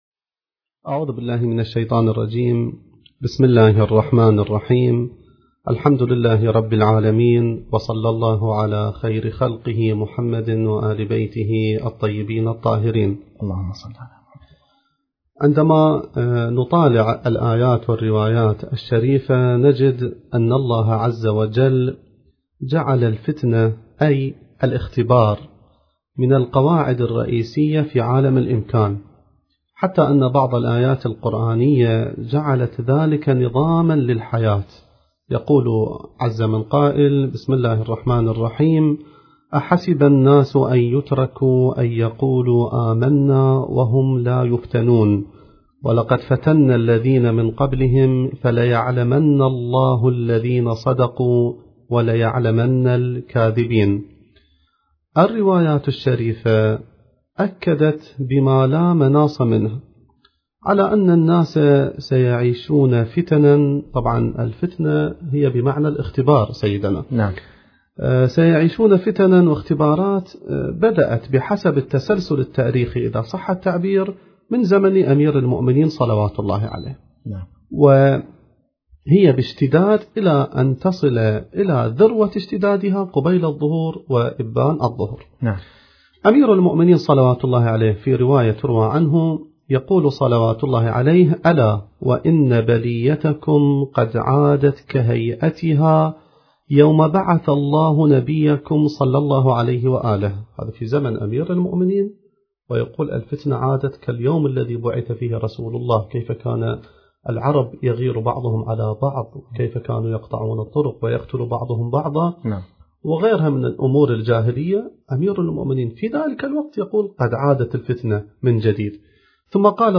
المكان: اذاعة الفرات